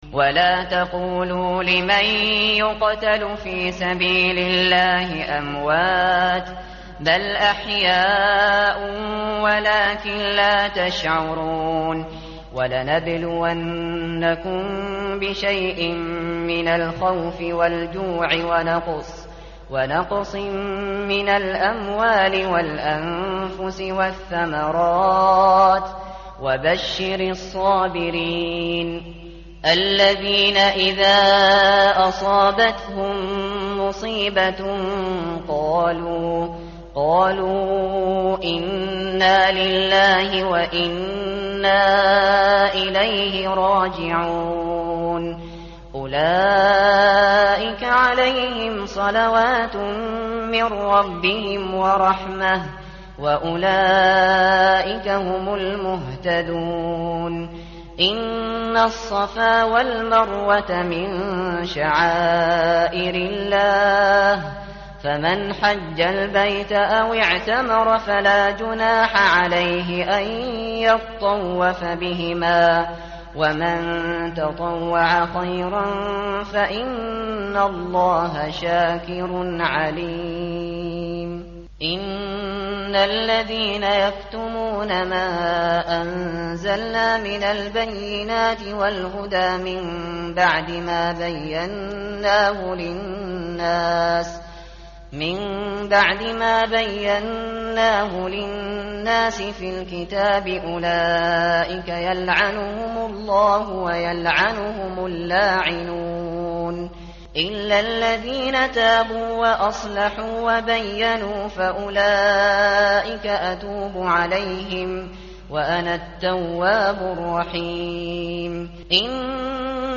tartil_shateri_page_024.mp3